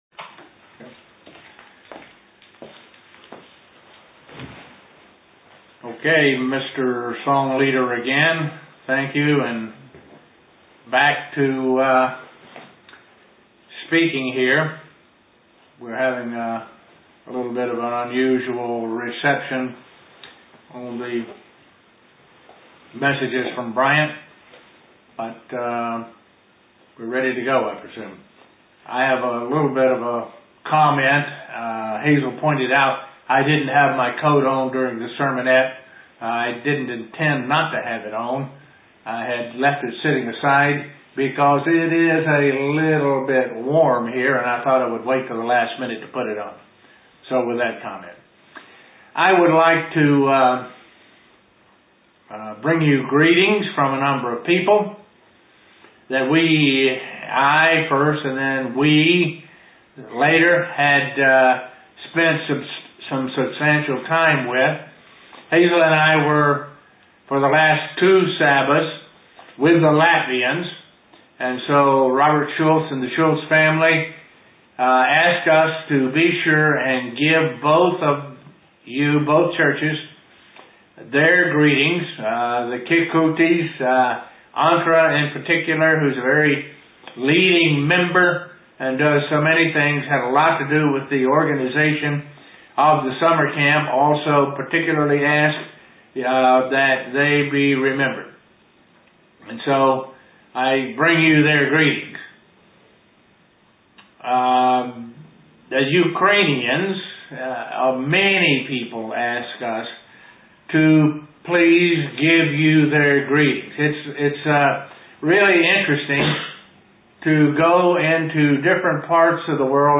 What a calling from God means. (Broadcast from Estonia)
(Broadcast from Estonia) UCG Sermon Studying the bible?